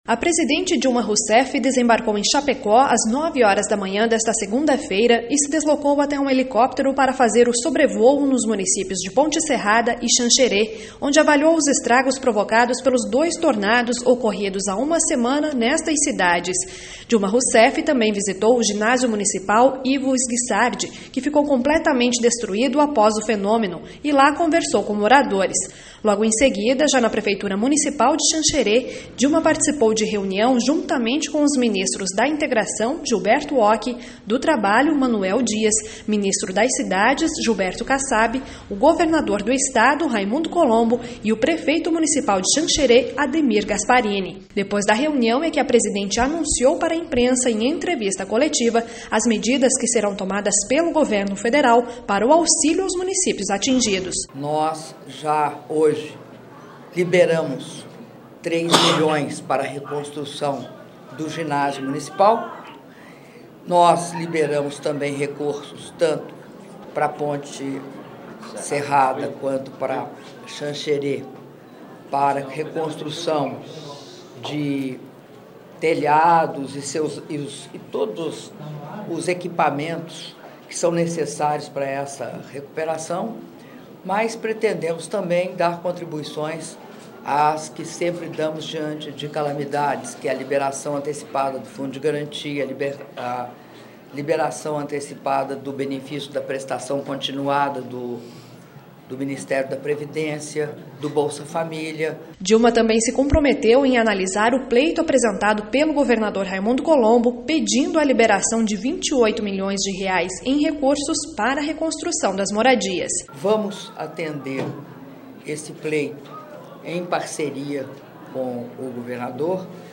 Sonora com: Dilma Rousseff, presidente da República.